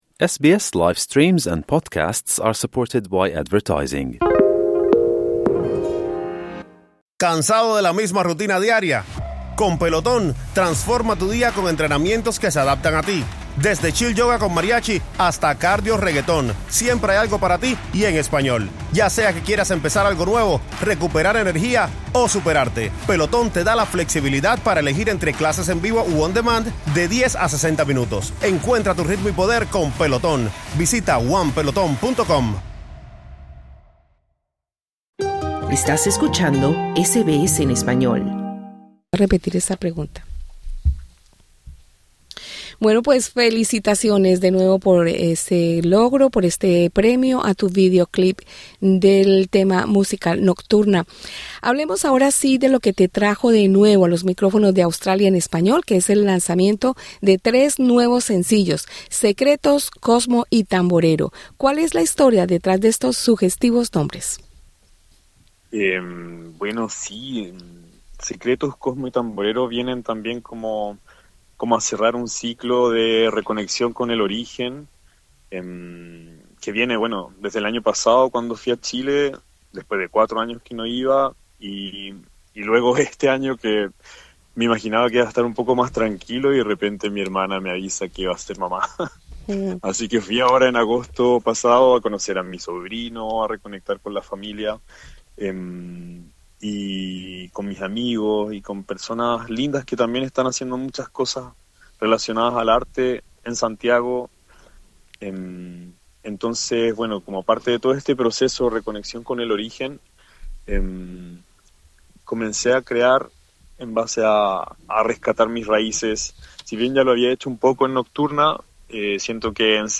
en entrevista con Australia en español